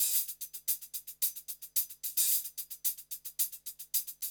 HIHAT LOP1.wav